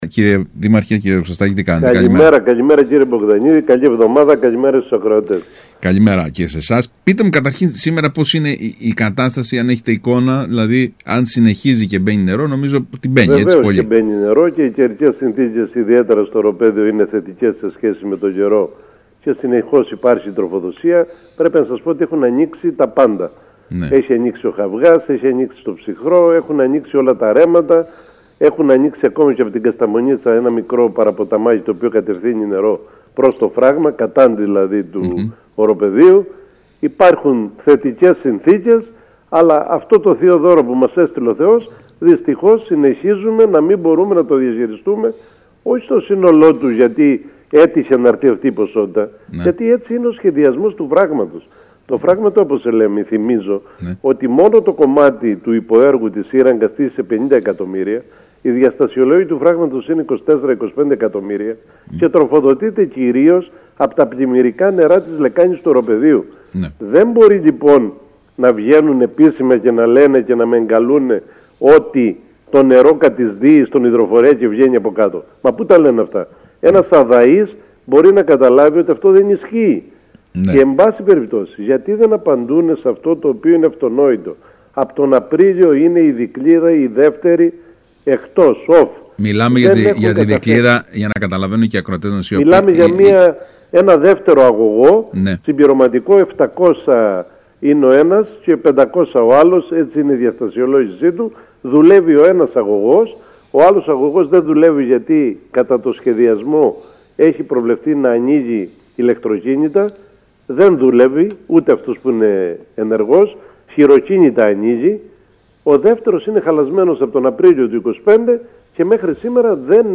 Σημαντικές διαστάσεις λαμβάνει η αντιπαράθεση για τη λειτουργία του Φράγματος Αποσελέμη, με τον δήμαρχο Χερσονήσου, Ζαχαρία Δοξαστάκη, να απαντά μέσω του ΣΚΑΪ Κρήτης στις ανακοινώσεις του Οργανισμός Ανάπτυξης Κρήτης και να καταγγέλλει σοβαρά προβλήματα στη διαχείριση του έργου.